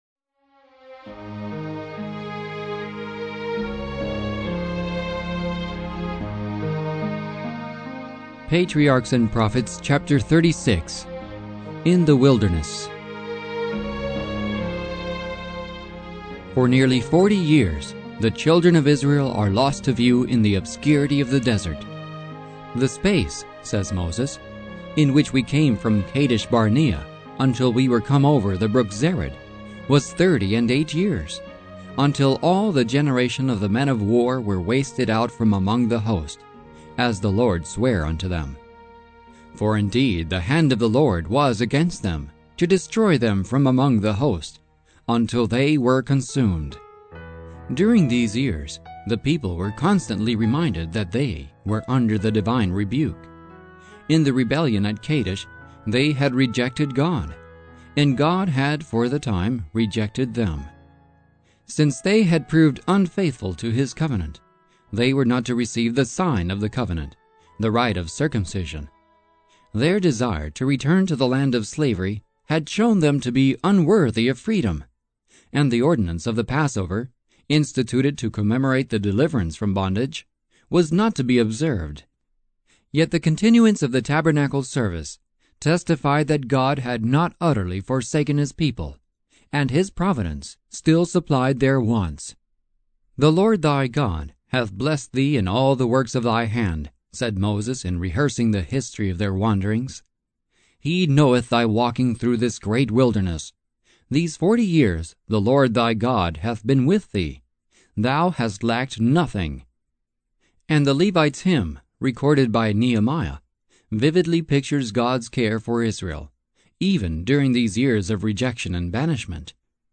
Patriarchs & Prophets MP3 Audio Book Patriarchs & Prophets MP3 Audio Book Patriarchs & Prophets MP3 Audio Book - 624MB sample DIGITAL DOWNLOAD SALES AGREEMENT I understand that this digital purchase must be downloaded over the internet.